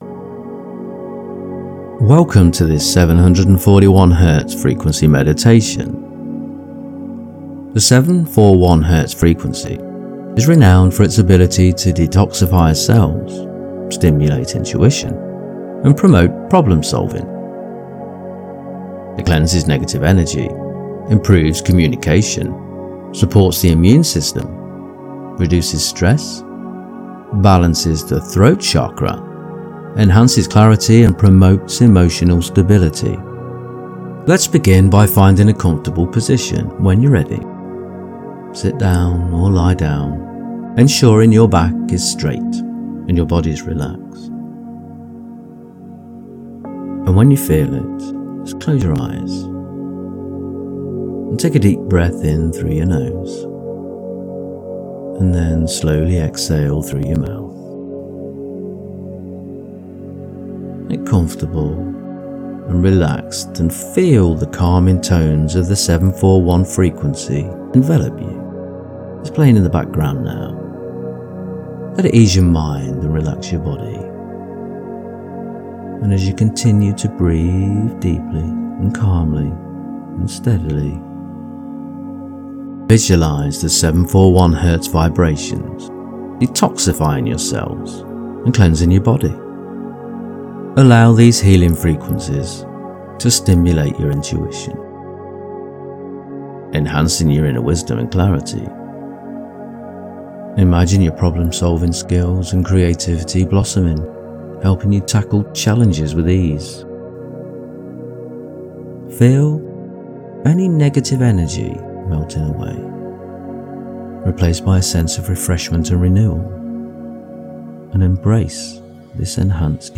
Meditate with 741 Hz music, focusing on developing your intuitive abilities.
741-throat-meditation.mp3